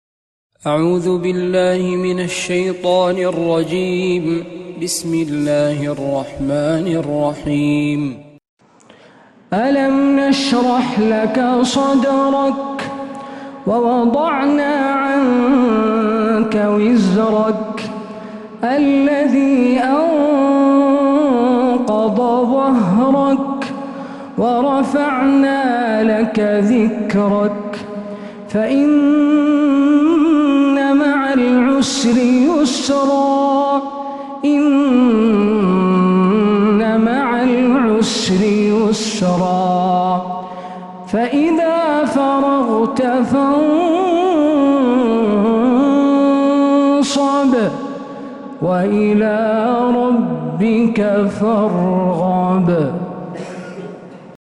سورة الشرح كاملة من مغربيات الحرم النبوي